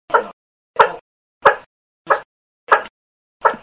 • 煮沸洗浄砂の音波形が鳴り砂の特徴である、魚の骨のような形を示していない原因は、雑音が入ったからであると思われる。
煮沸洗浄した砂の音スペクトル
右の測定は、雑音が入っていて、波形が崩れています。